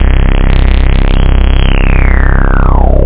303-anolog.resonancebass
Amiga 8-bit Sampled Voice
303-anolog.mp3